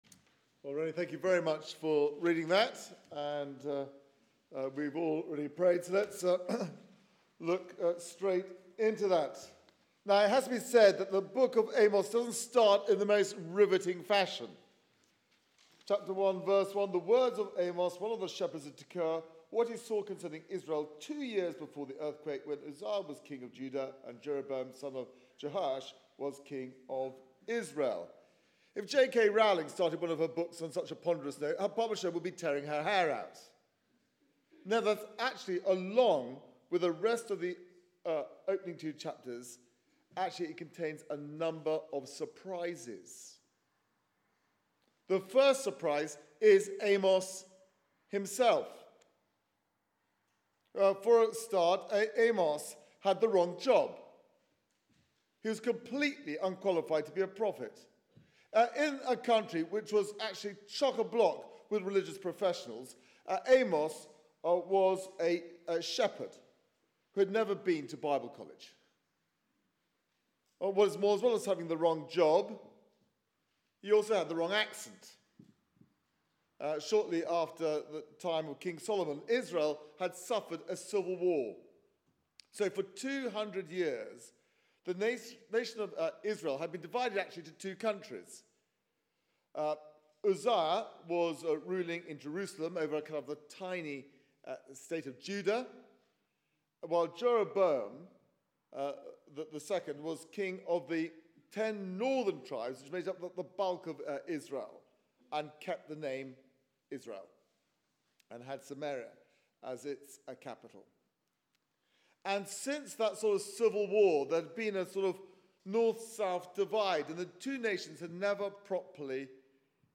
Media for 6:30pm Service on Sun 05th Feb 2017 18:30 Speaker
Amos 1-2 Series: Prepare to meet your God Theme: Your God cares Sermon